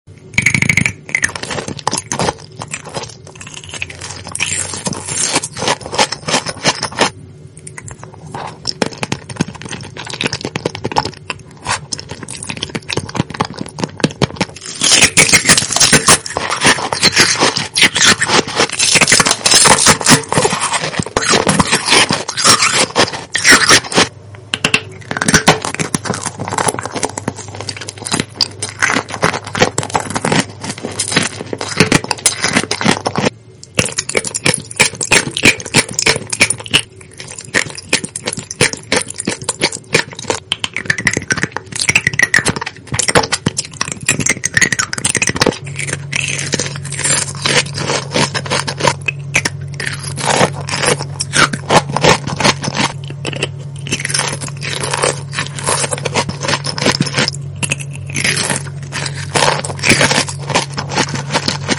ASMR RED PURPLE EMOJI FOOD sound effects free download
ASMR RED PURPLE EMOJI FOOD kohakuto jelly sprinkle honey jelly ice popping boba marshmallow tanghulu mukbang eating sounds